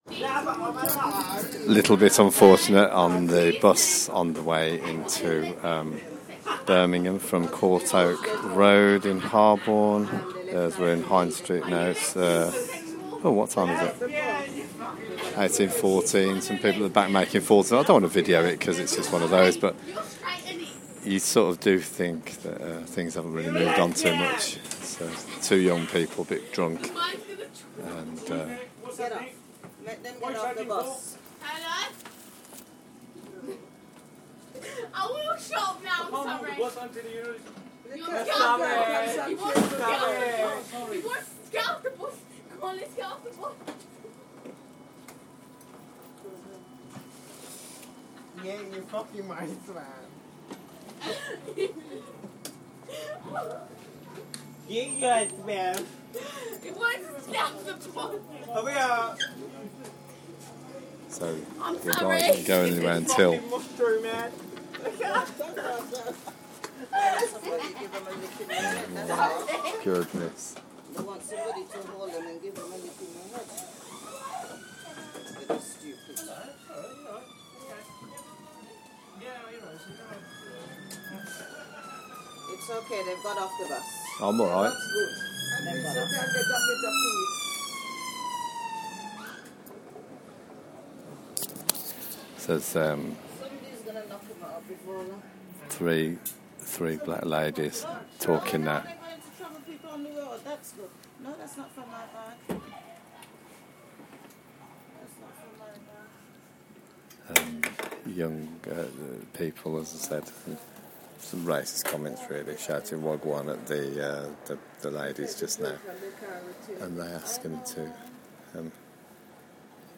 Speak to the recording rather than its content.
the alternative universe on the bus i am travelling on!